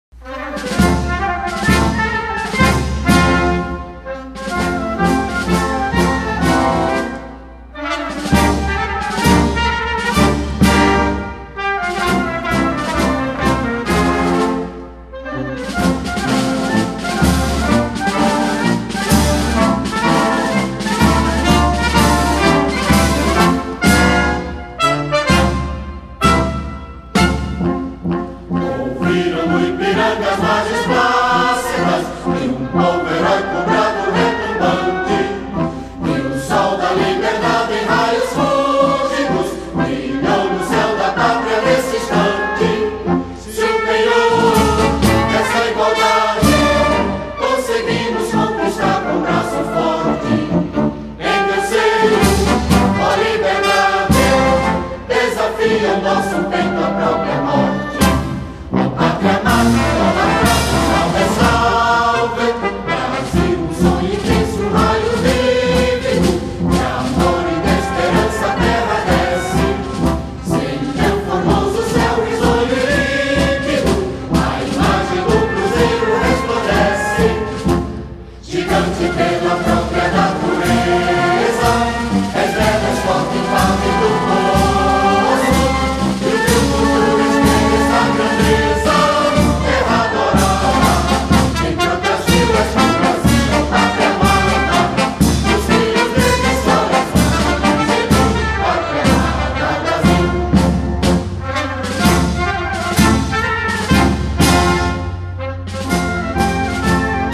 I'll play a national anthem, you tell me which country has it played before their World Cup games.
Anthem 4